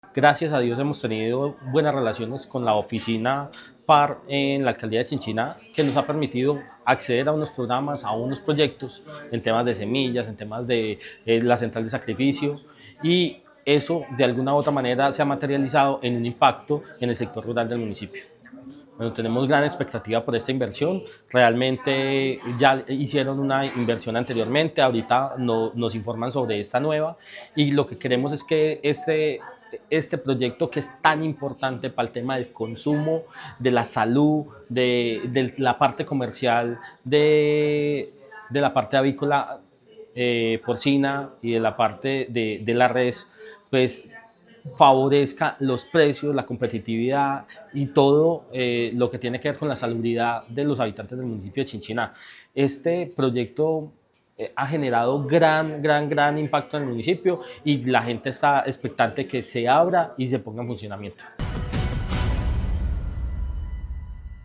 Felipe Jaramillo, Concejal de Chinchiná.
Felipe-Jaramillo-concejal-de-Chinchina.mp3